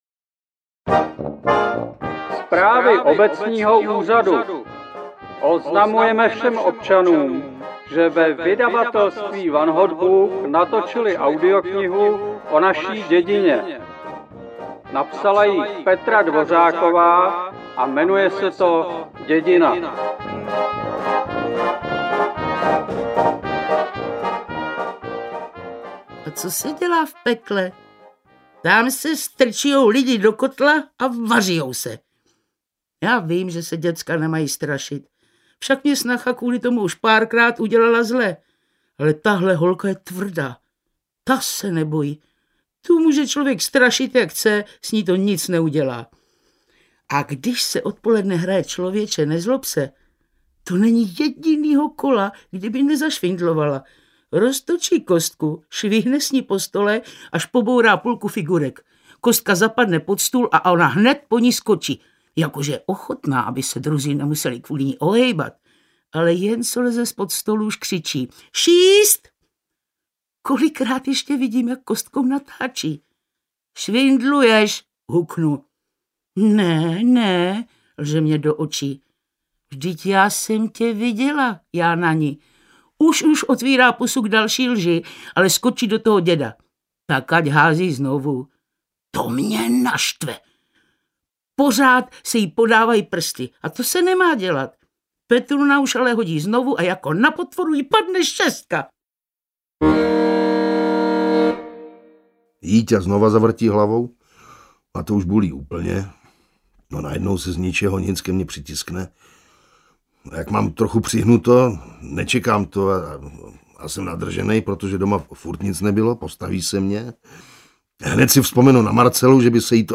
Dědina audiokniha